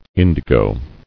[in·di·go]